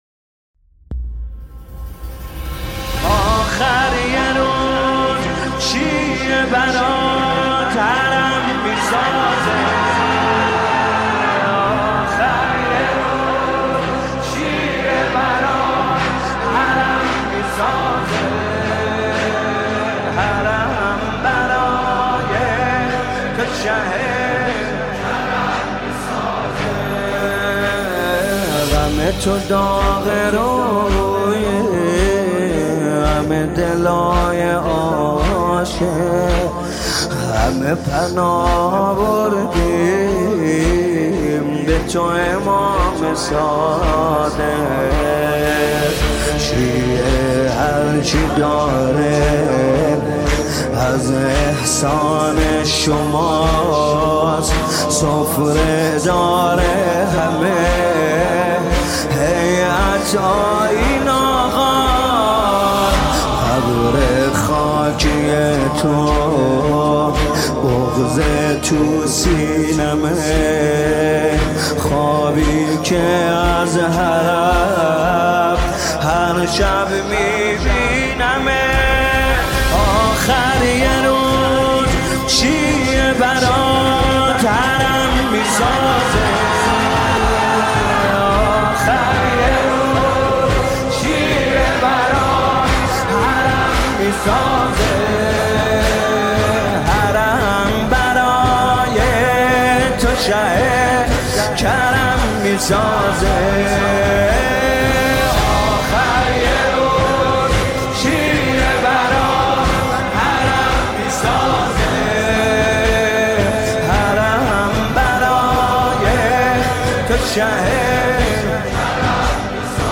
مذهبی
مداحی استودیویی